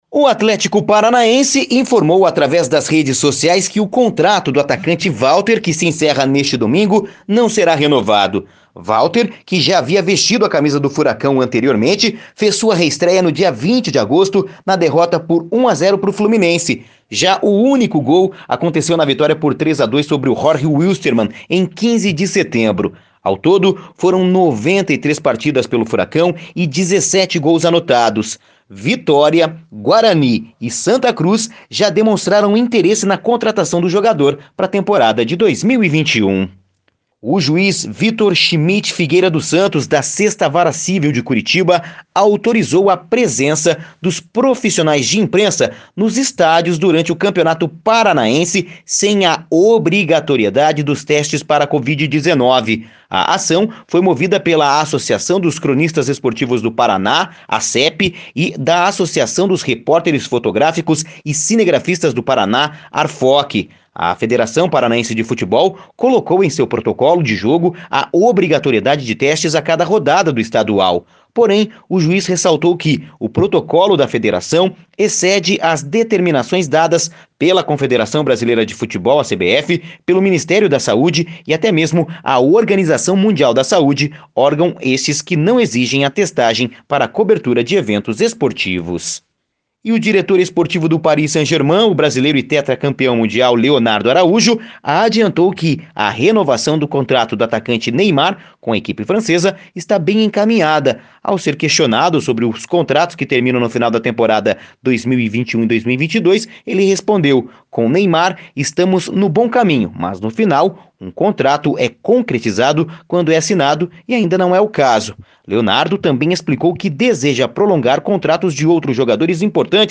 Giro Esportivo (SEM TRILHA)